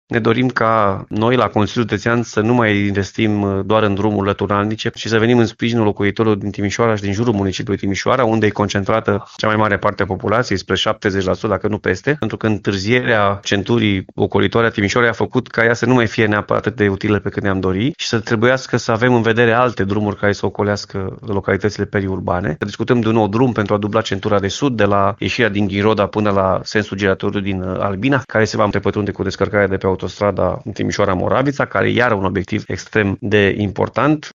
Președintele CJ Timiș mai spune că în domeniul infrastructurii rutiere administrația pe care o conduce se va concentra pe drumurile mai circulate.